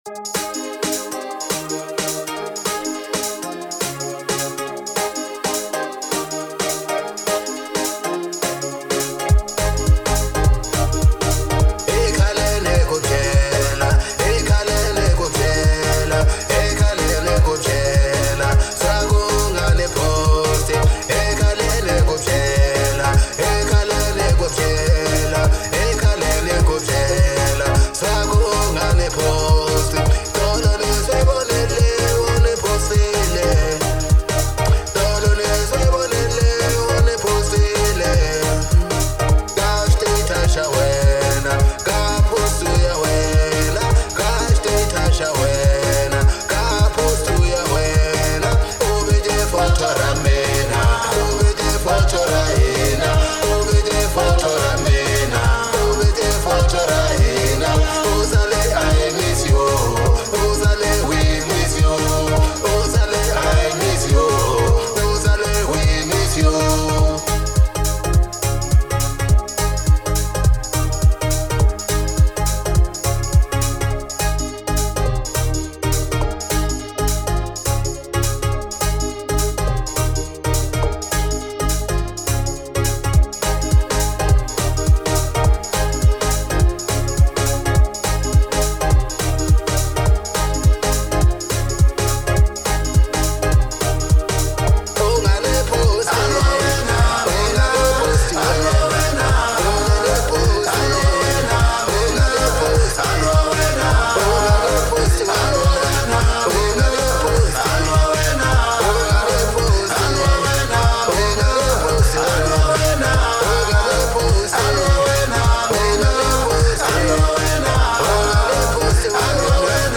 04:21 Genre : African Disco Size